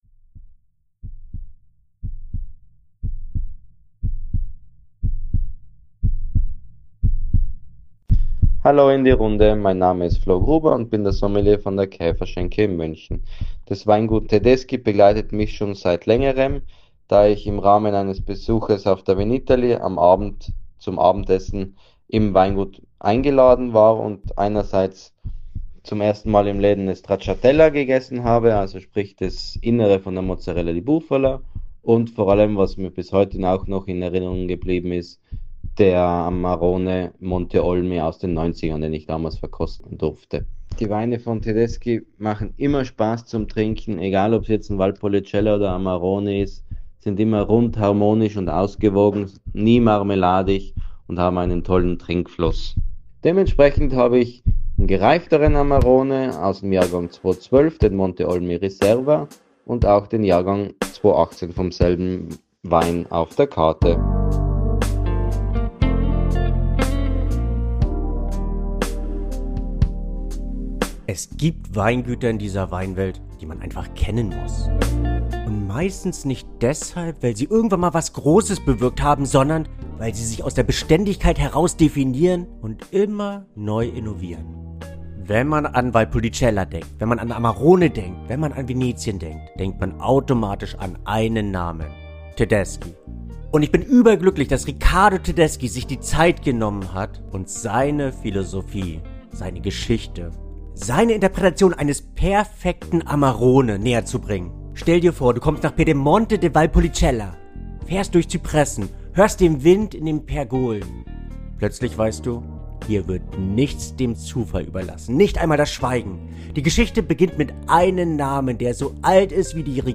Featurette ~ SOMMELIER on Tour Podcast